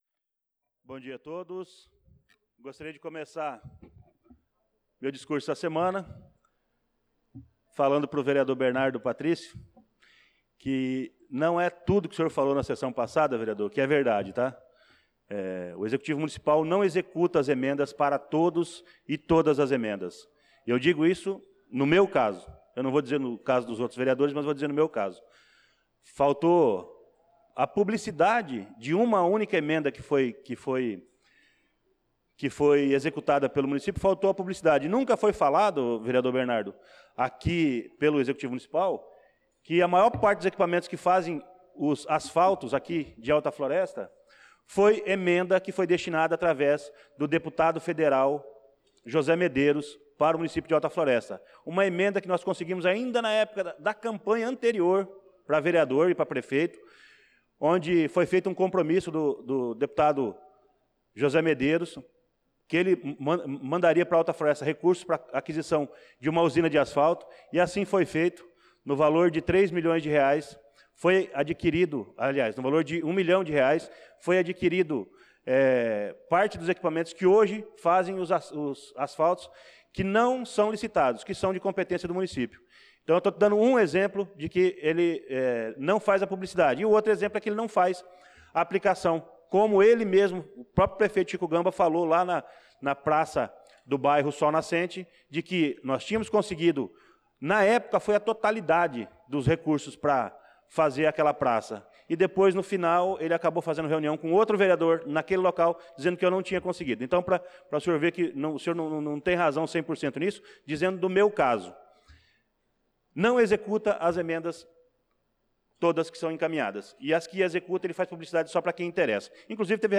Pronunciamento do vereador Luciano Silva na Sessão Ordinária do dia 26/05/2025